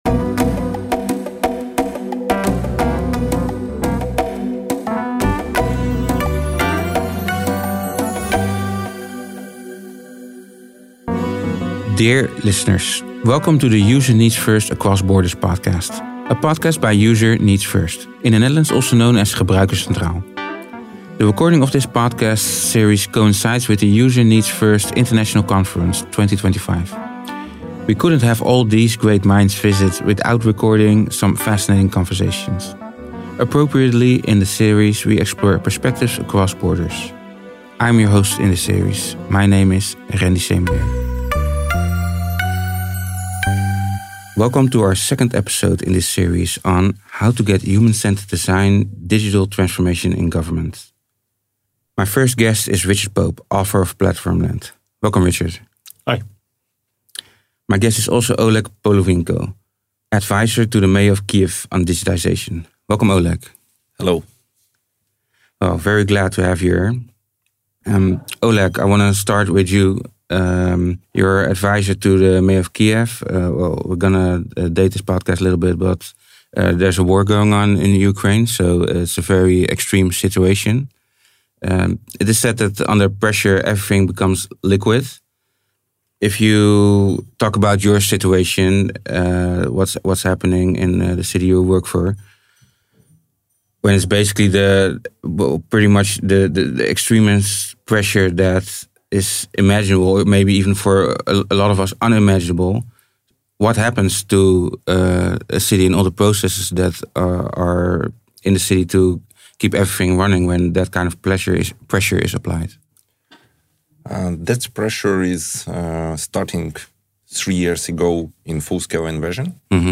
In the podcast series 'User Needs First Across Borders', we talk to international speakers who were guests at the User Needs First International Conference 2025.